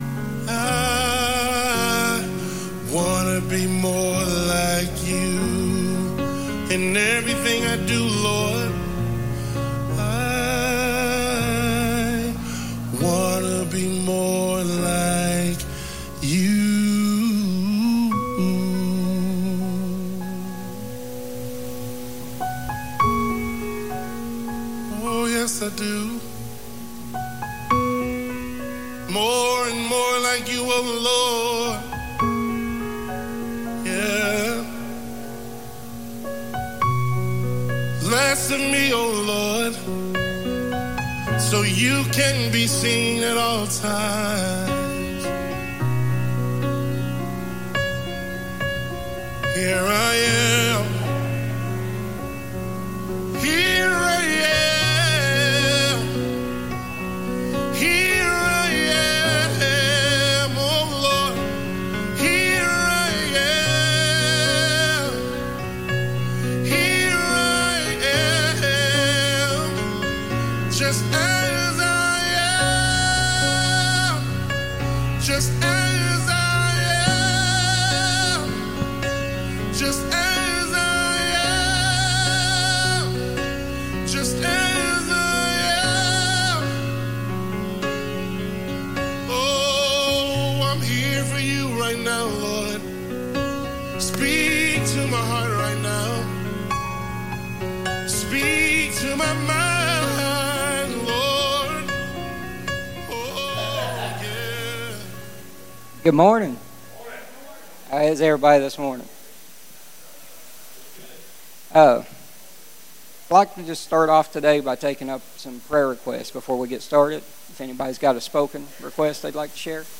Sunday Morning Teaching